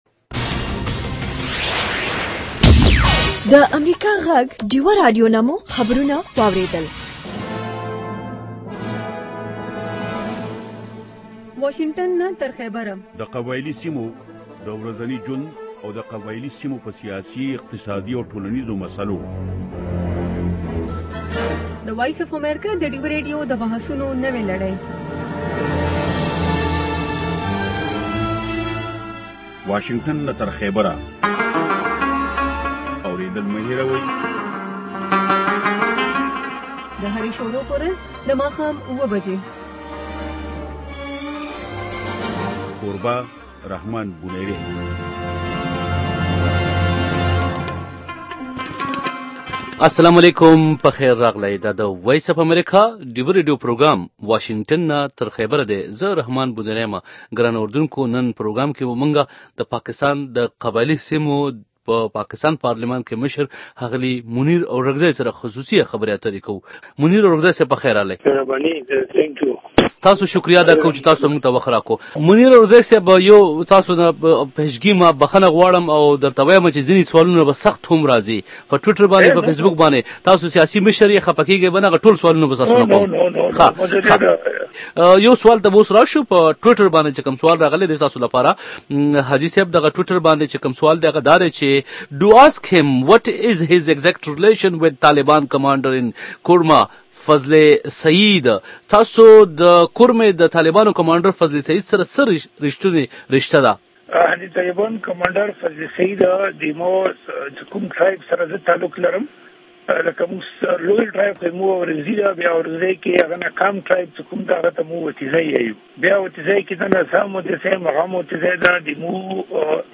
د سوال ځواب دغه برخه